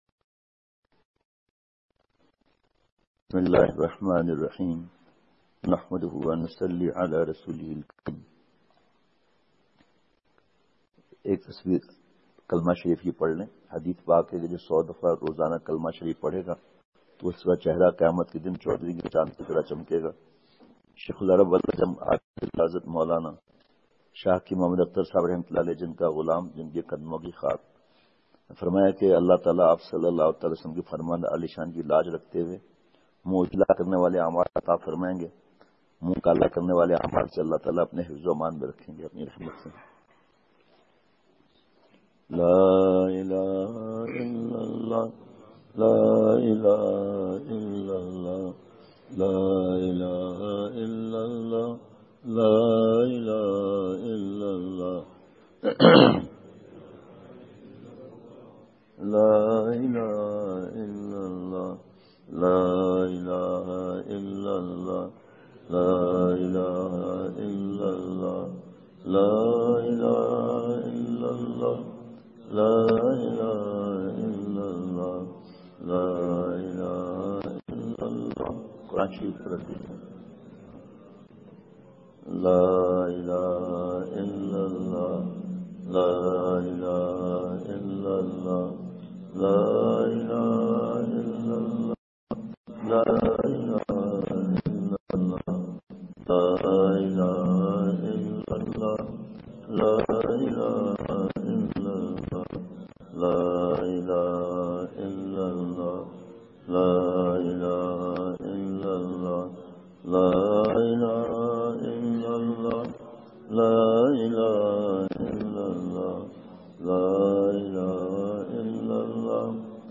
*یہ بیان بہت ہی خاص بیان ہے فجر کی نماز پڑھ کر بہت ہی درد بھرے انداز میں بیان فرمایا ۔۔۔*